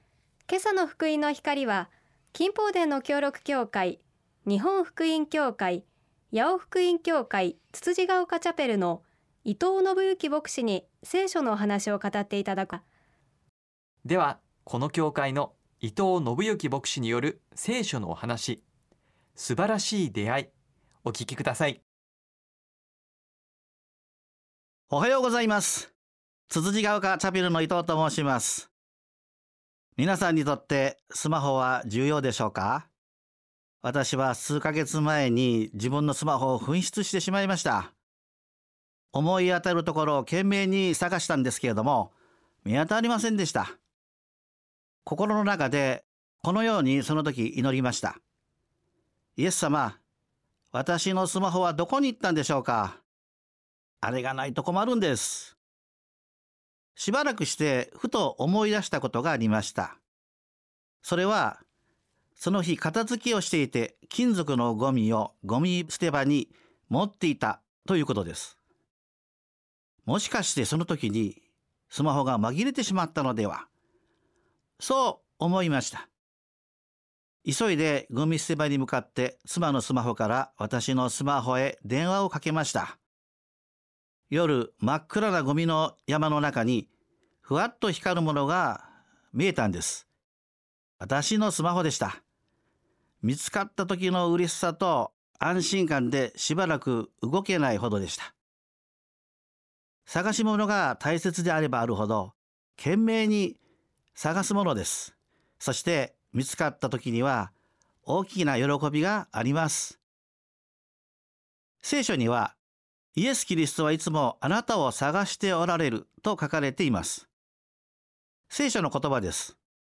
聖書のお話「素晴らしい出会い」